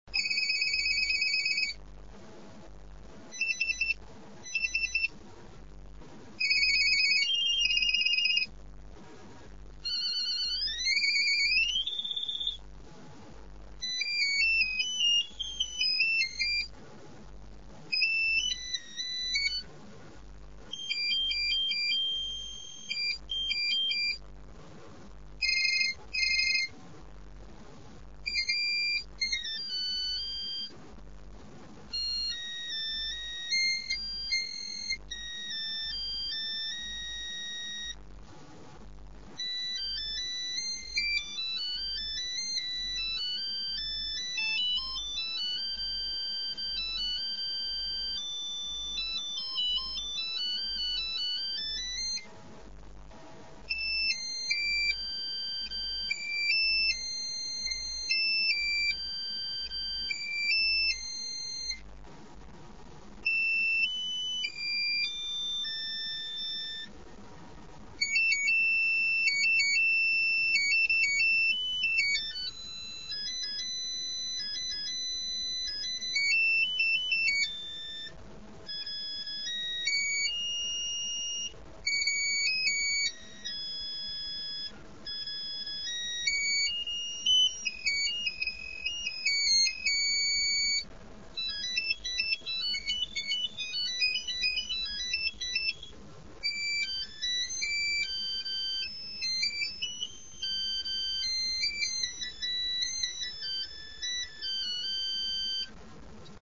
Всего можно выбрать из 17 типов звонка, которые имеют названия тип1, тип2 и так далее. Записать свою мелодию нельзя, а представленные мелодии очень аскетичны.